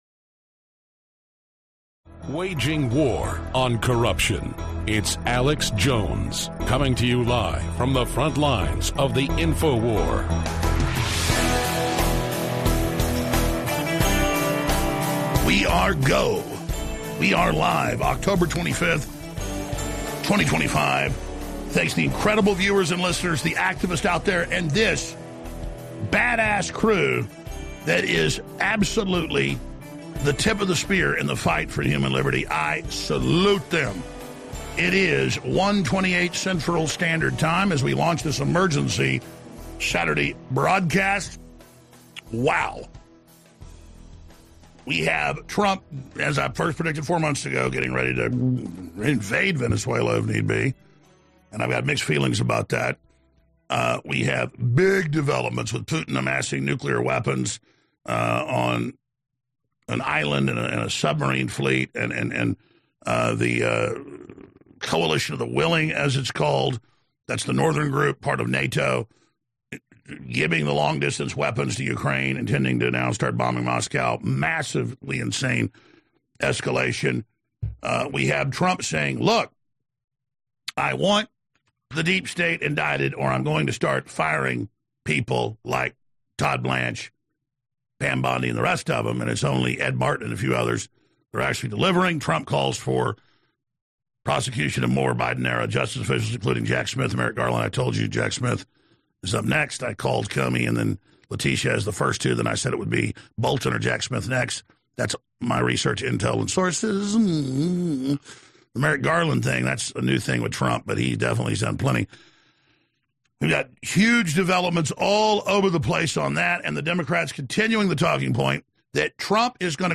Alex Jones and Infowars Shows Commercial Free